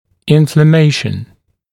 [ˌɪnflə’meɪʃn][ˌинфлэ’мэйшн]воспаление